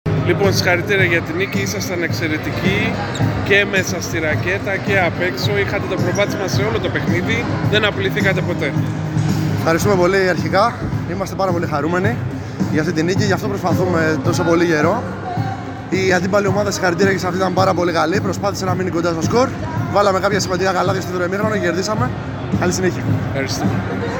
GAME INTERVIEWS:
Παίκτης REAL CONSULTING